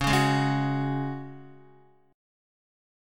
C#sus4 chord